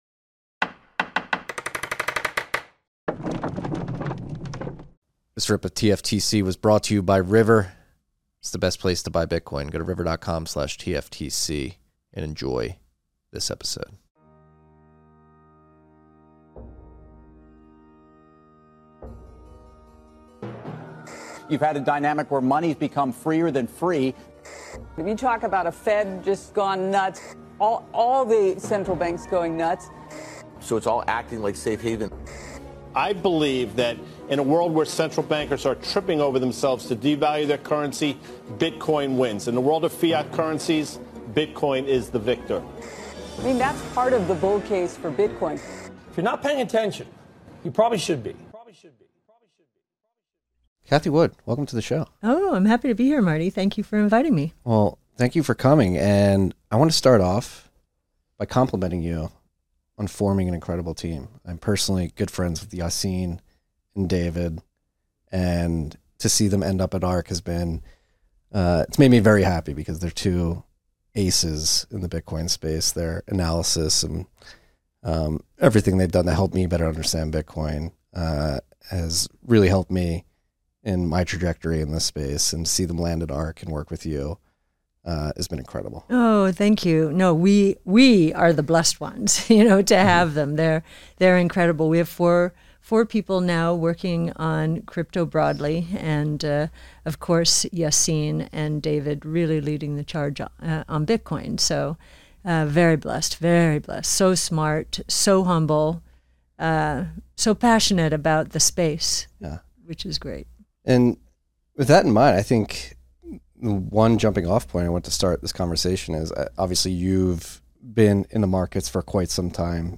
interviews Cathie Wood